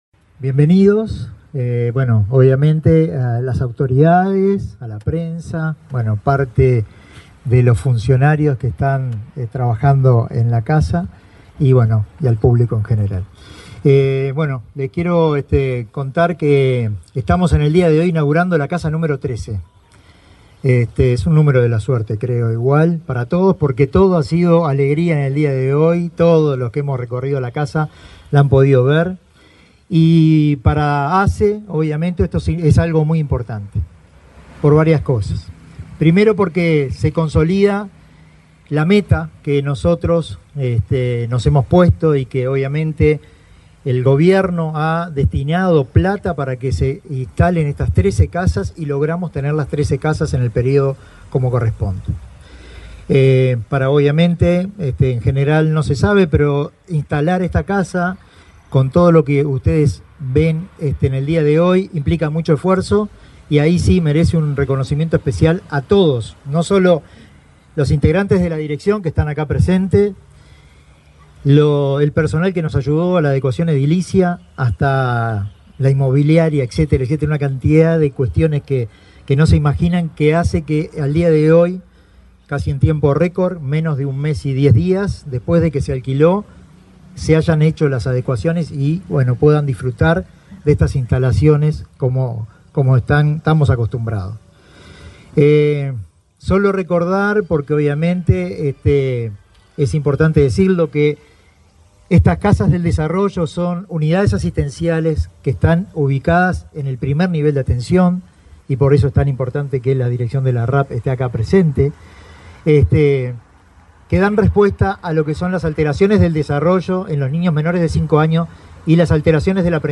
Palabras de autoridades de ASSE en Maldonado